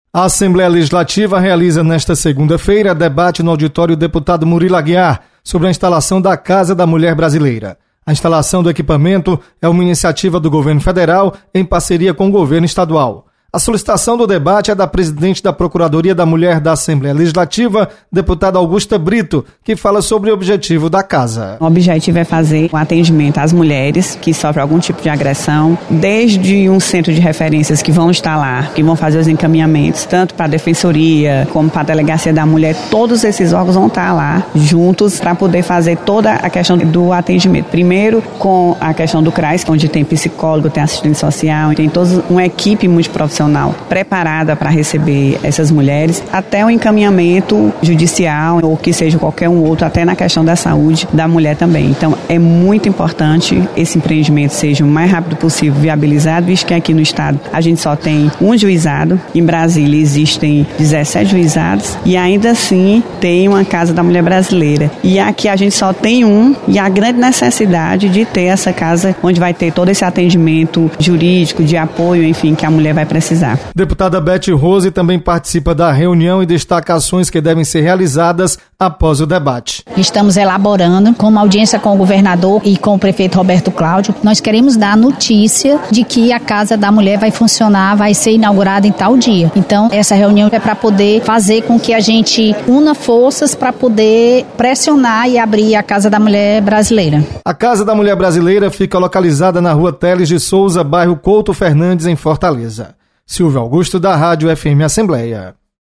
Deputada Augusta Brito destaca importância da instalação da Casa da Mulher Brasileira.